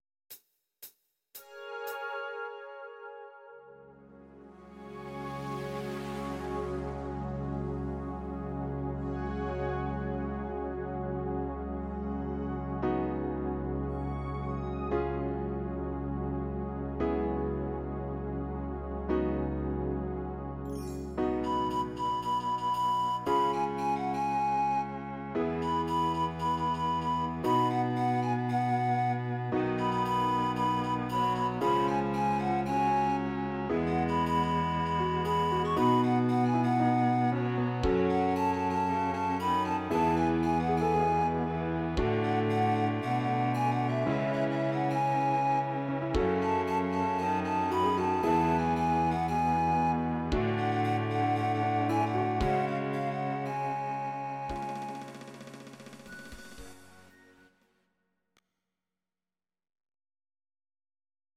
Ab
Audio Recordings based on Midi-files
Pop, 1980s